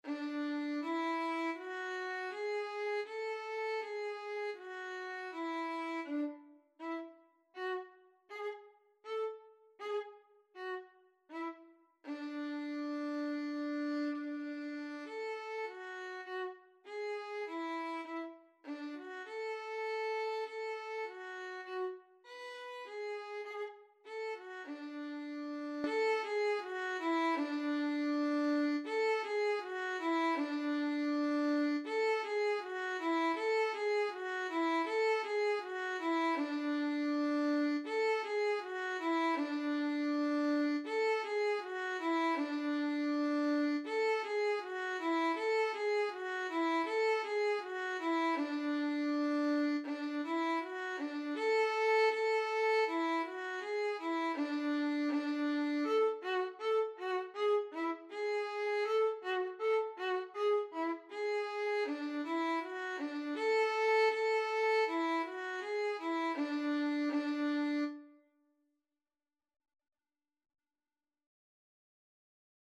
4/4 (View more 4/4 Music)
D5-B5
Instrument:
Violin  (View more Beginners Violin Music)
Classical (View more Classical Violin Music)